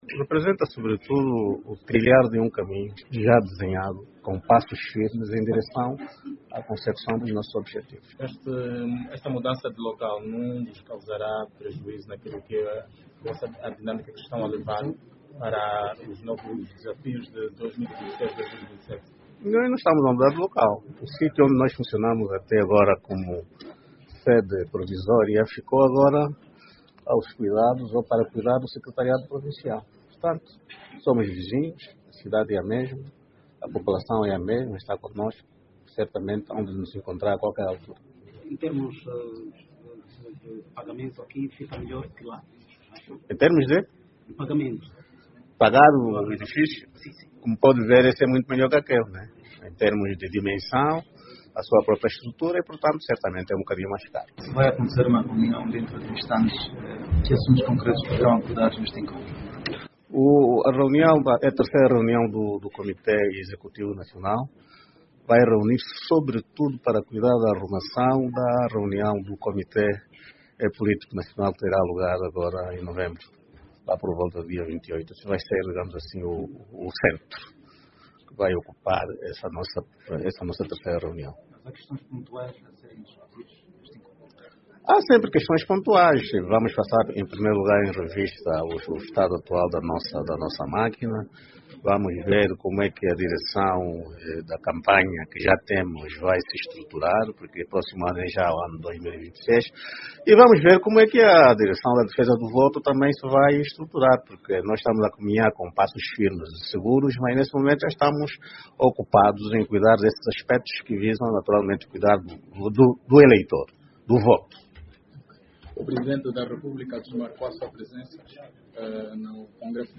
que falava à imprensa nesta terça-feira, 28 de outubro, à margem da inauguração da nova sede do partido, no município da Maianga, em Luanda.